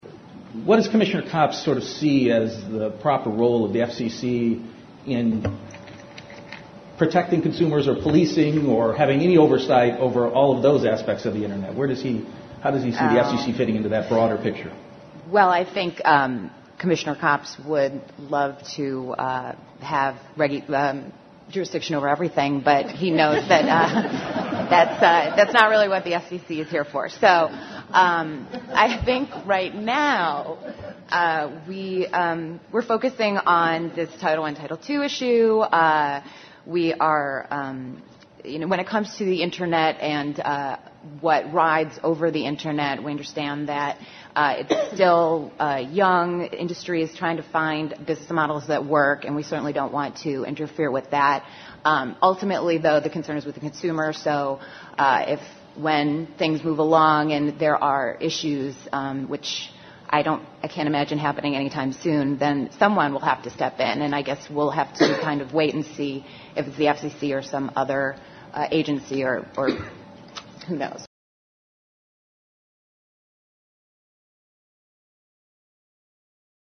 She let the cat out of the bag at the NCTA's 2010 Cable Show last week: